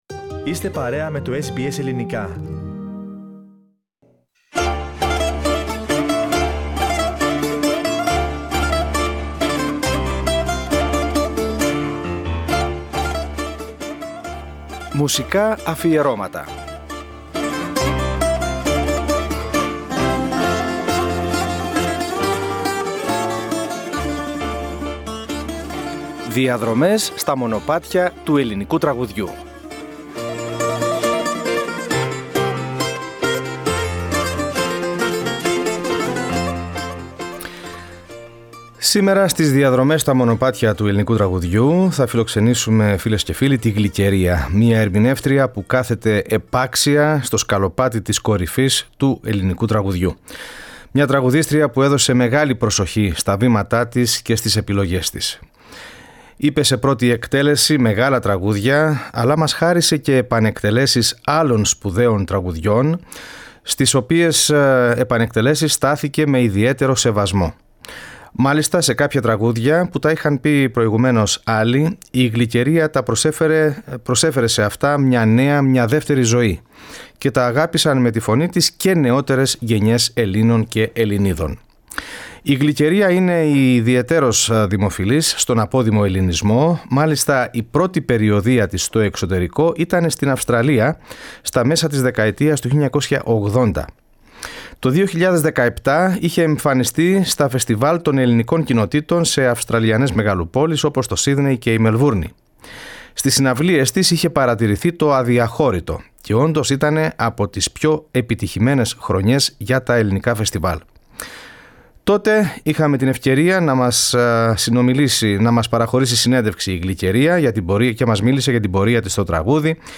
Πρόκειται για ένα μουσικό – και όχι μόνο – αφιέρωμα, με την επιλογή αγαπημένων κομματιών της να διανθίζεται με αποσπάσματα συνέντευξης που είχε παραχωρήσει στο SBS Greek το 2017.